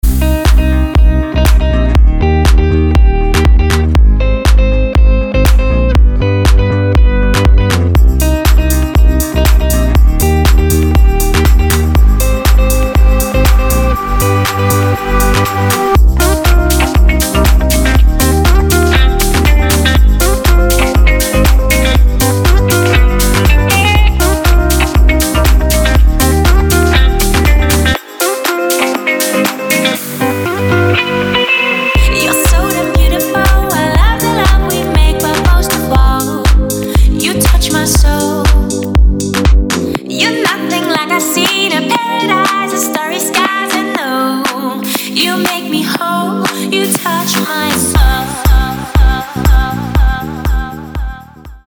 • Качество: 320, Stereo
красивые
женский вокал
deep house
dance
Electronic
спокойные
чувственные
электрогитара
красивый женский голос